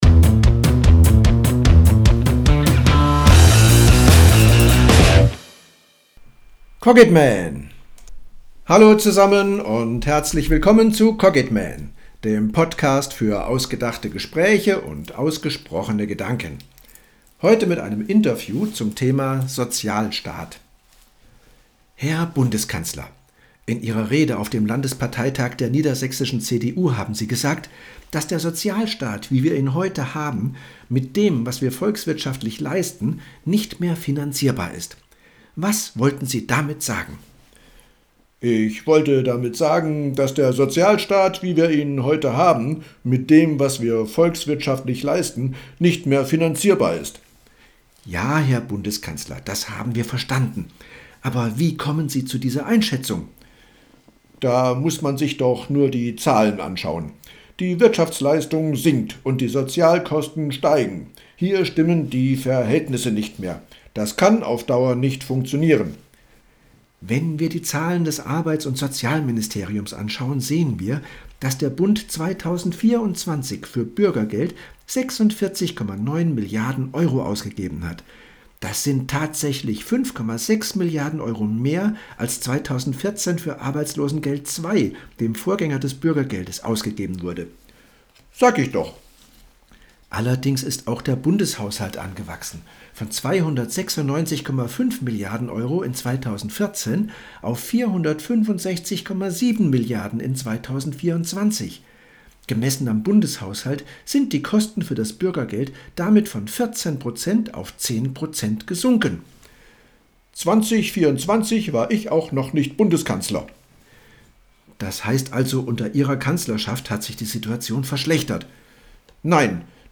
Dialog_Sozialstaat.mp3